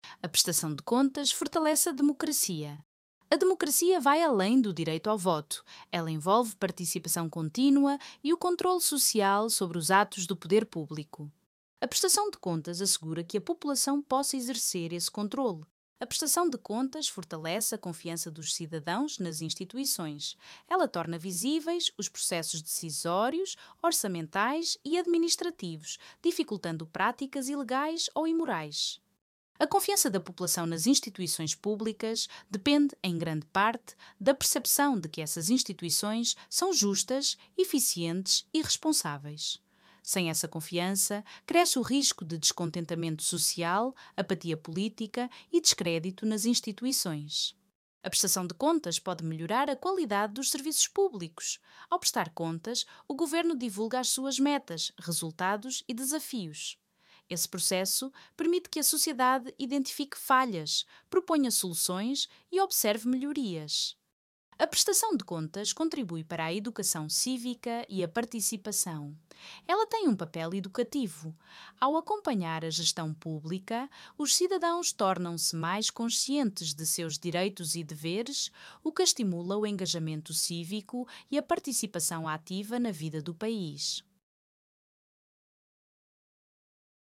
🎧 A facilitadora pode nos dar mais informações.